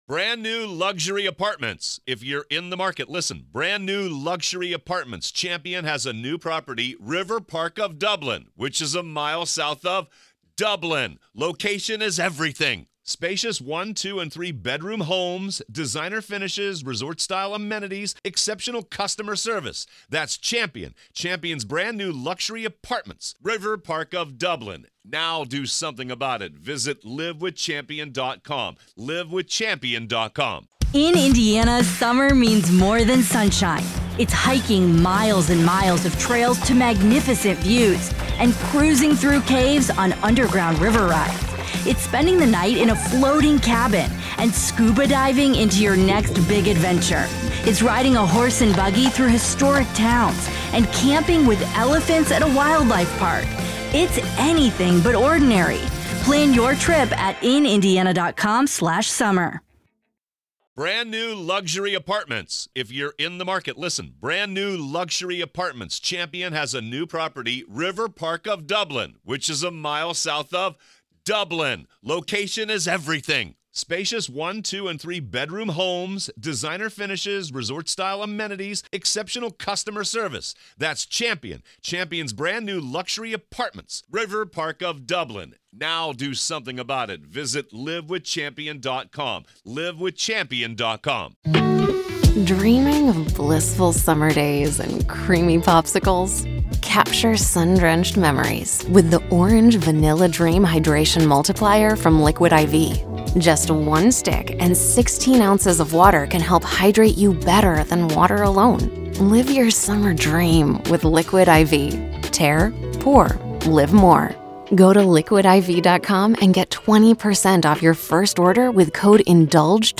In this conversation